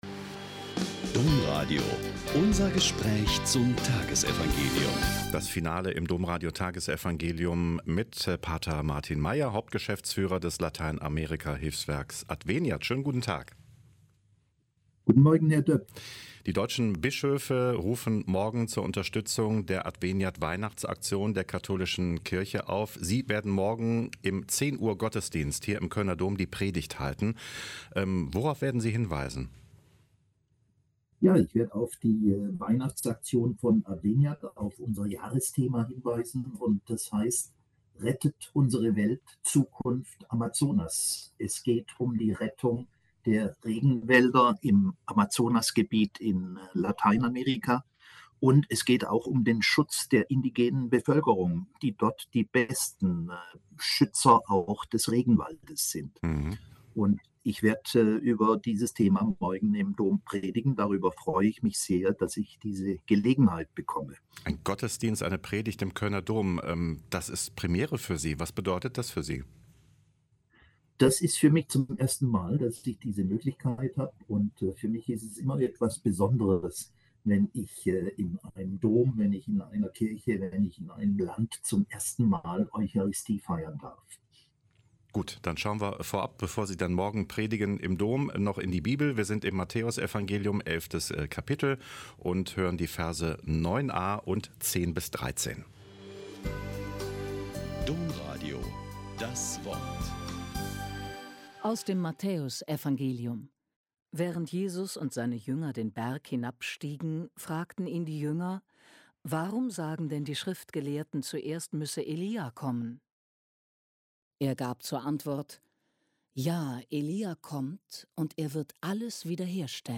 Mt 17,9a.10-13 - Gespräch